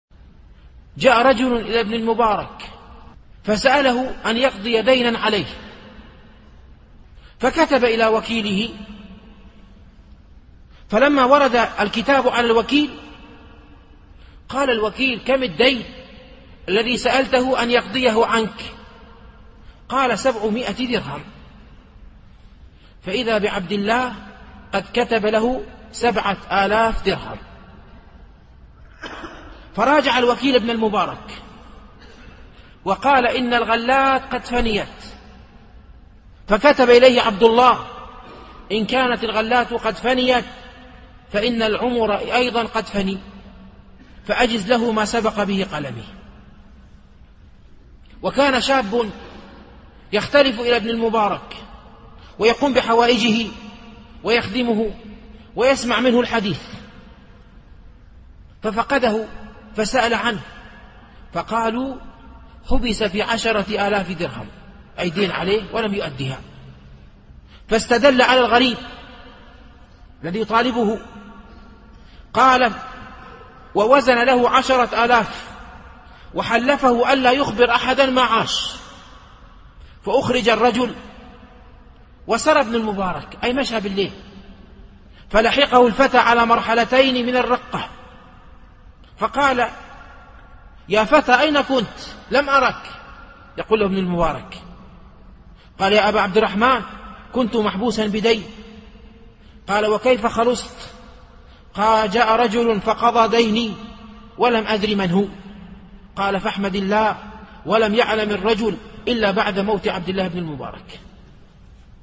القسم: لقاء مفتوح